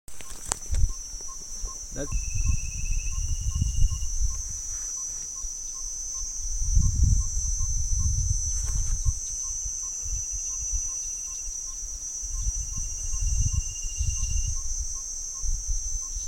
Nome em Inglês: Yellow-rumped Tinkerbird
Localidade ou área protegida: Parque Nacional Lago Mburo
Condição: Selvagem
Certeza: Gravado Vocal
yellow-rumped-tinkebird.mp3